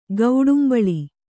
♪ gauḍumbaḷi